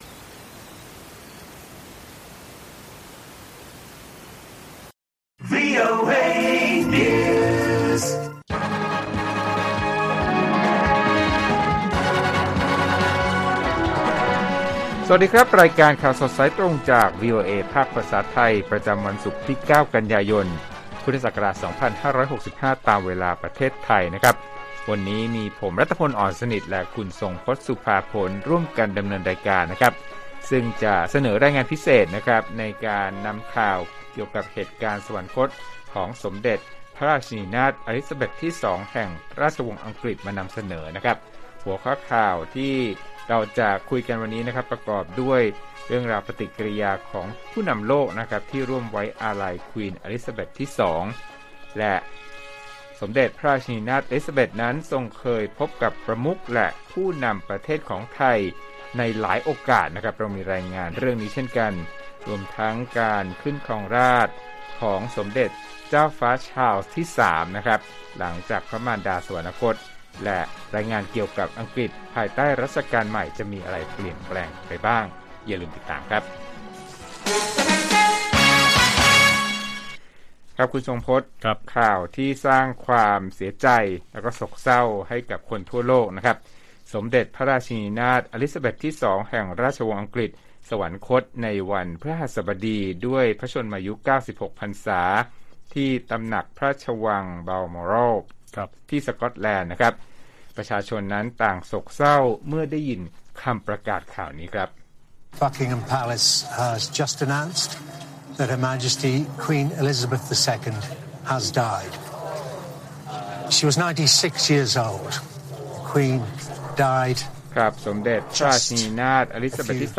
ข่าวสดสายตรงจากวีโอเอไทย 6:30 – 7:00 น. วันที่ 9 ก.ย. 65